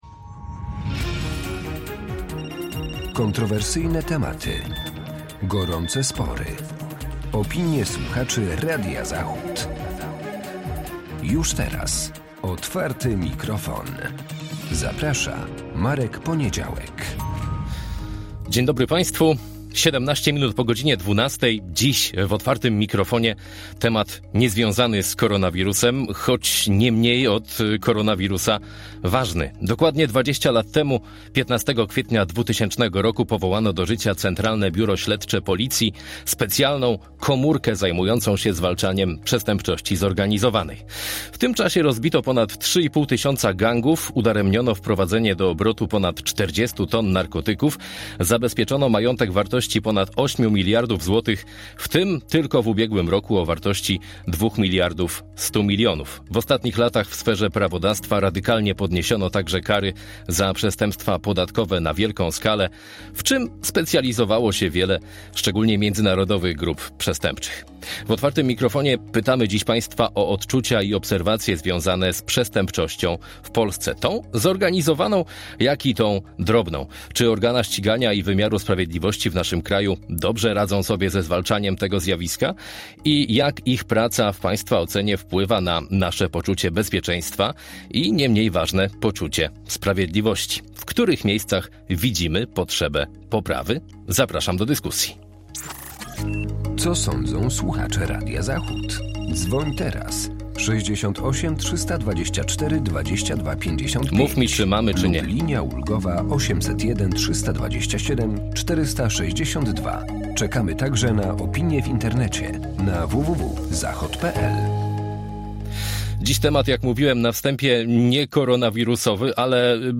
W Otwartym Mikrofonie pytamy Państwa odczucia i obserwacje związane z przestępczością w Polsce.